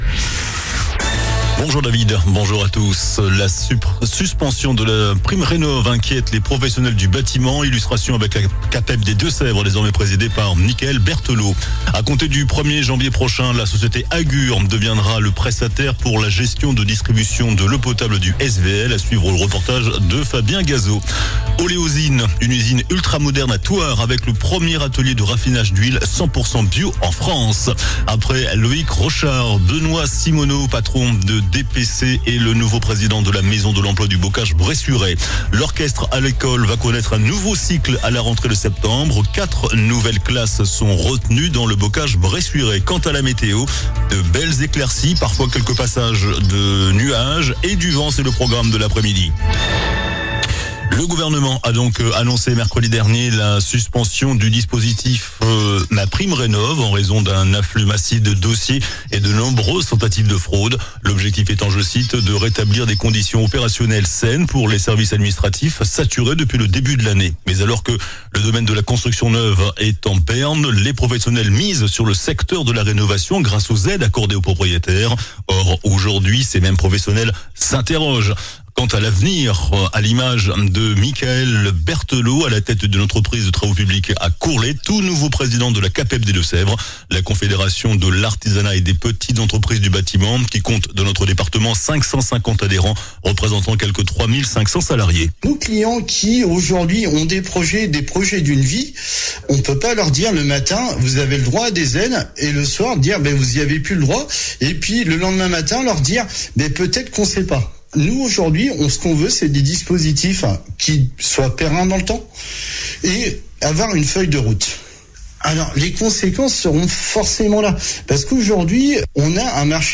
JOURNAL DU VENDREDI 06 JUIN ( MIDI )